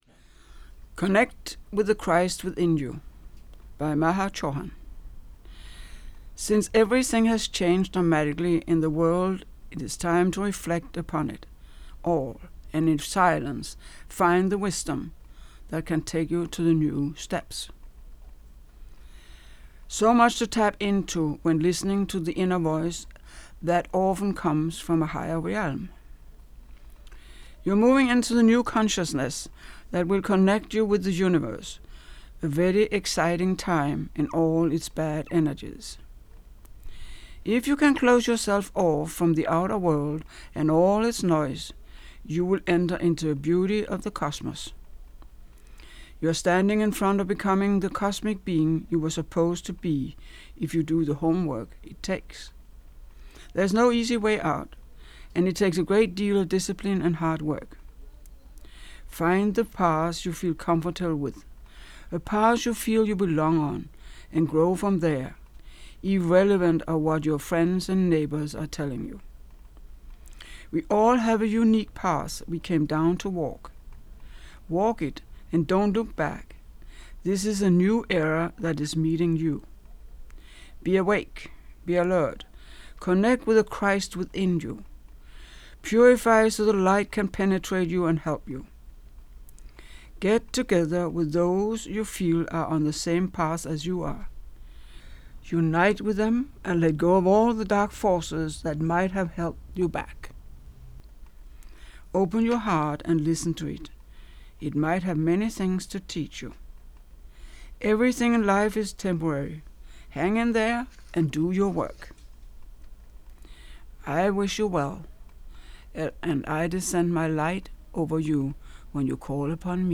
Your strong voice always comforts me.